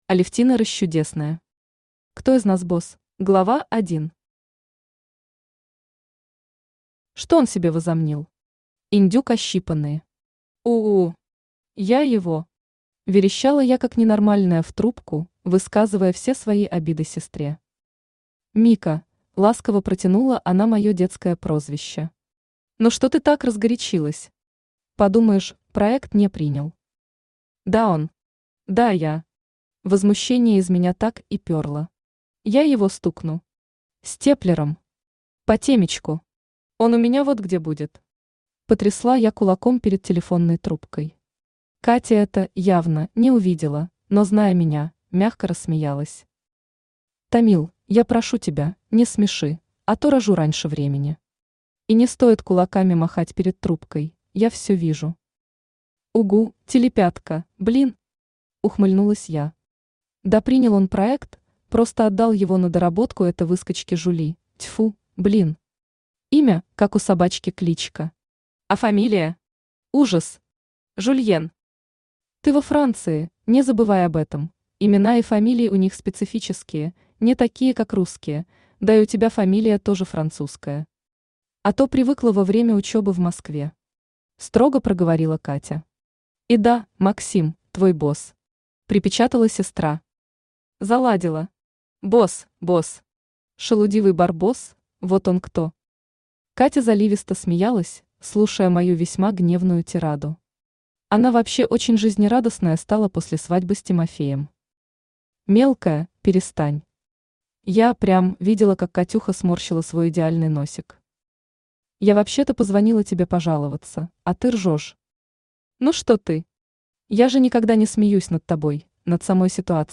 Аудиокнига Кто из нас босс?
Автор Алевтина Расчудесная Читает аудиокнигу Авточтец ЛитРес.